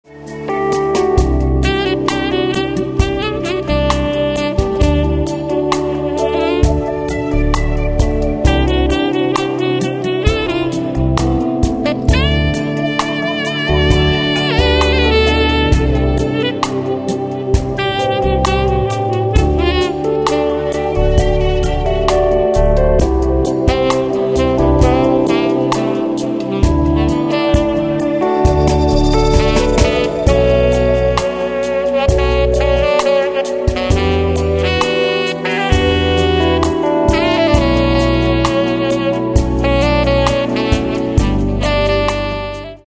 Tenor-Saxophon